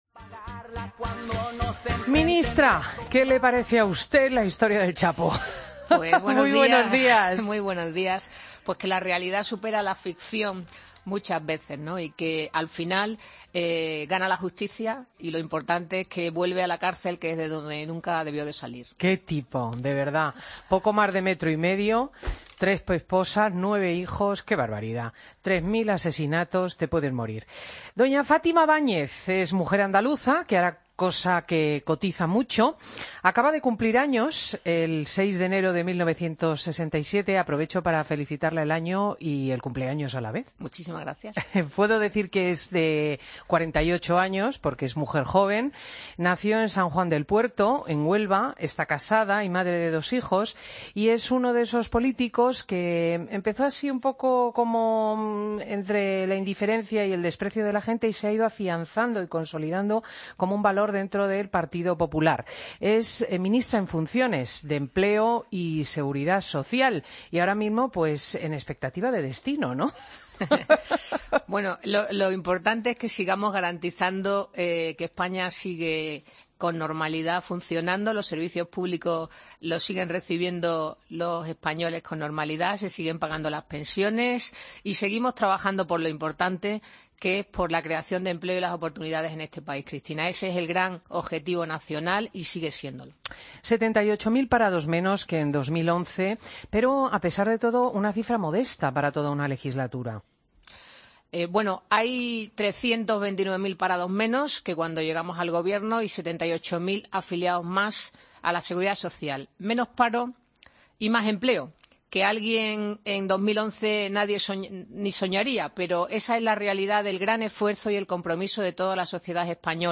AUDIO: Escucha la entrevista a Fátima Báñez, Ministra en funciones de Empleo, en Fin de Semana.